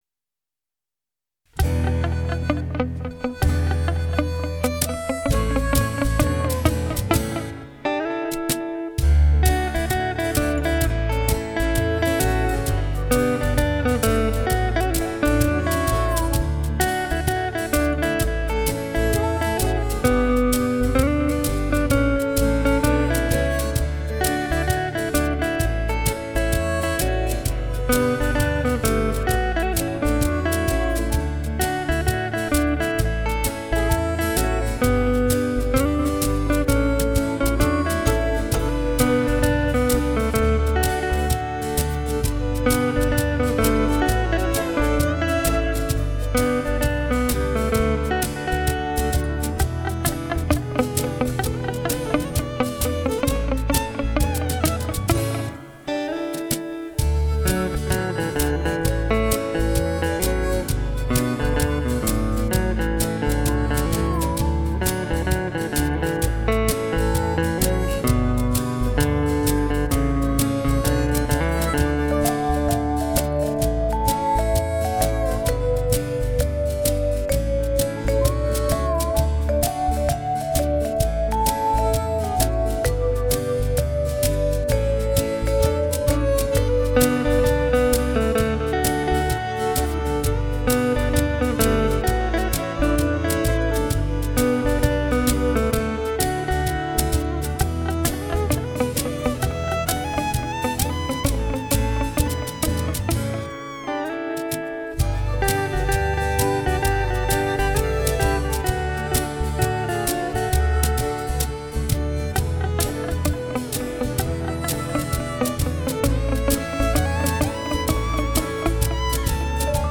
country guitar